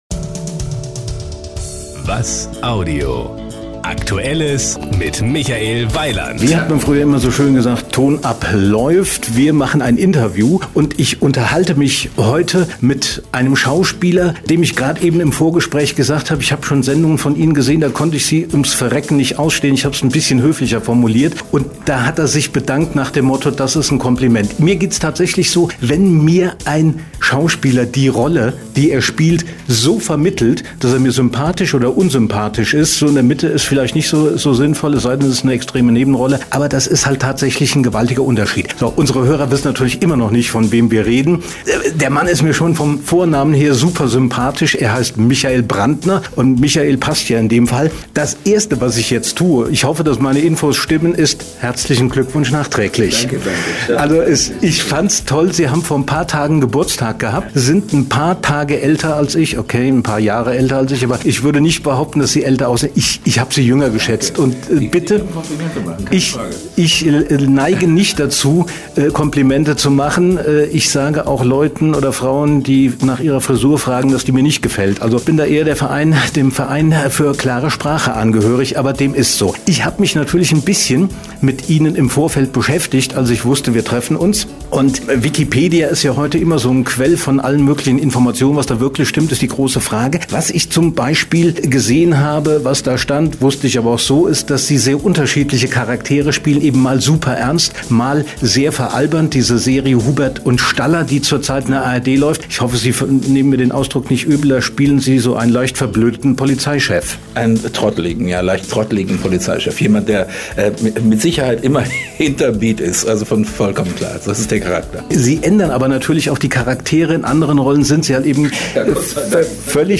Sie sind hier: Start » Interviews » Interviews 2016